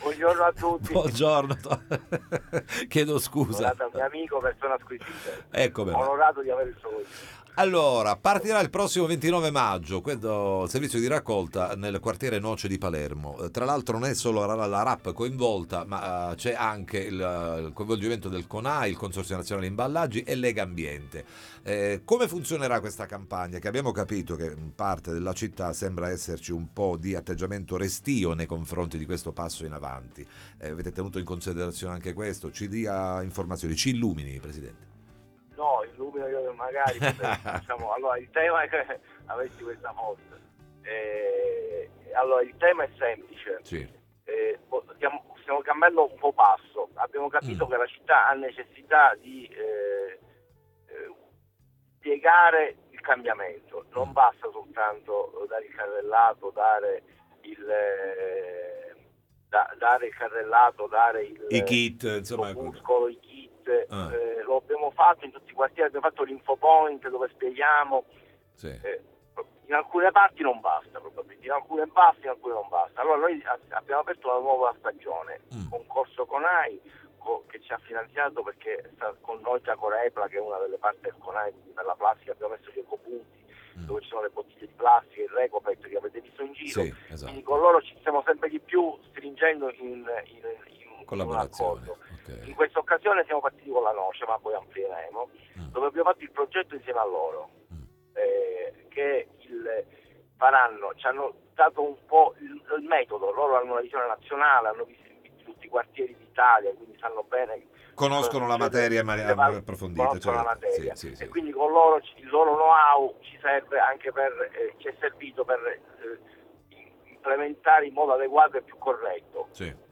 Raccolta differenziata al quartiere della Noce Interviste Time Magazine 24/04/2026 12:00:00 AM